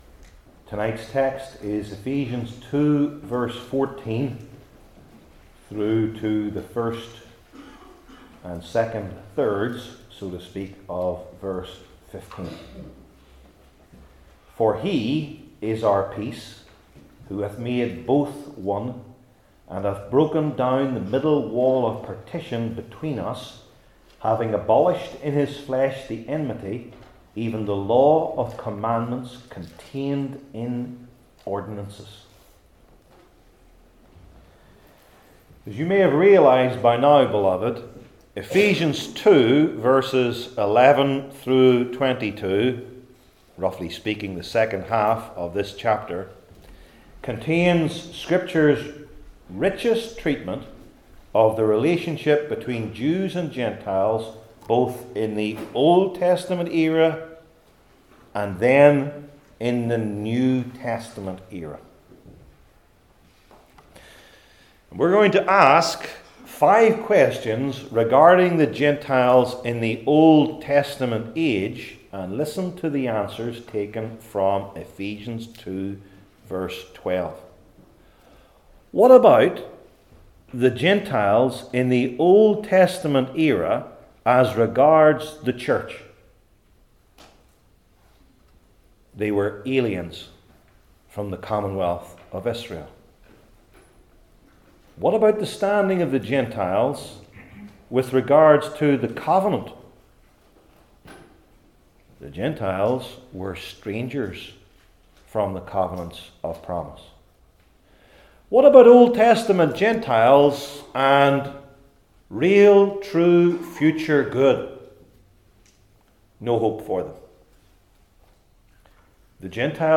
New Testament Sermon Series I. The Meaning II.